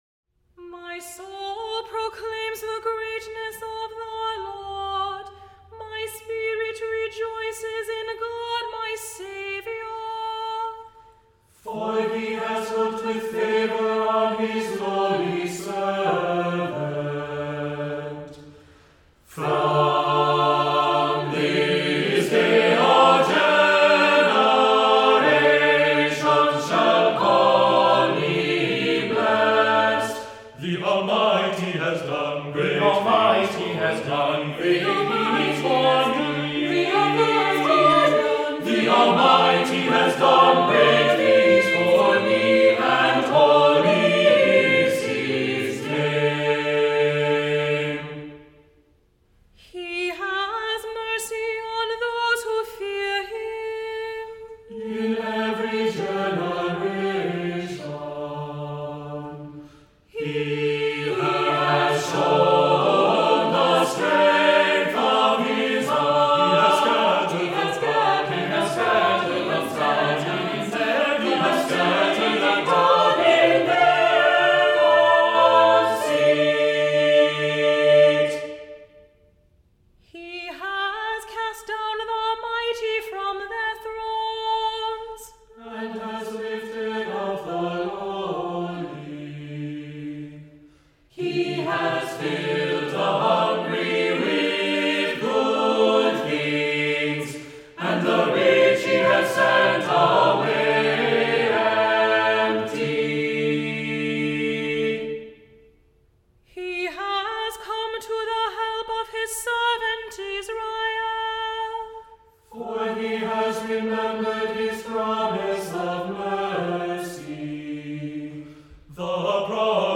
Voicing: SATB a cappella; cantor; opt. assembly